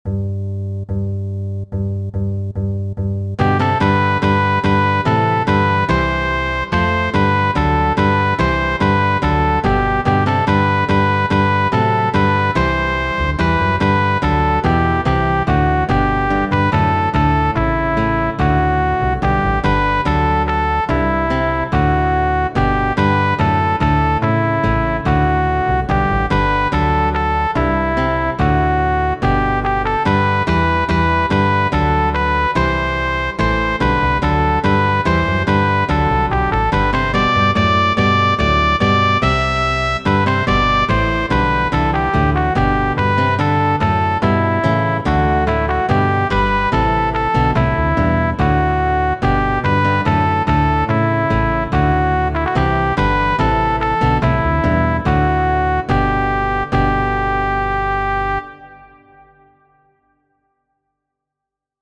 Zusammenspiel im Orchester:
Vier- und Fünstimmigkeit